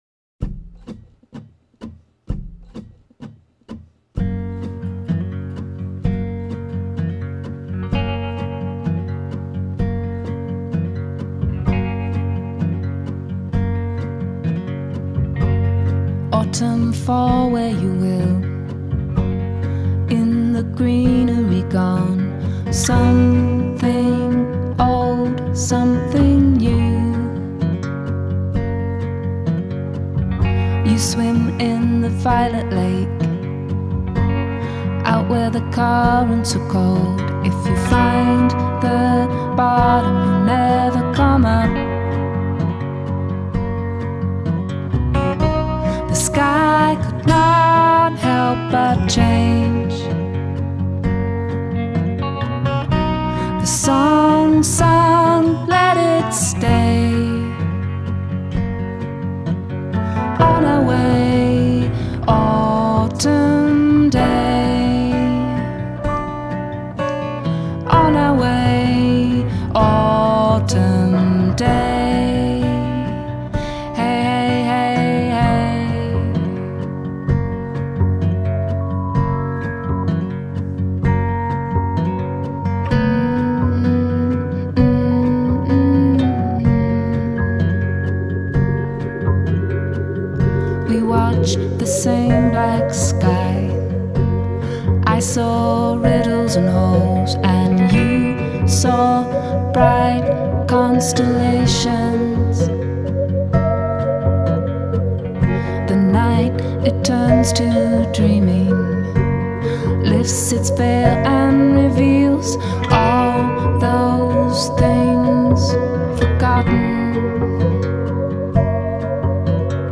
Her music puts you in a zone, it transports you.
drums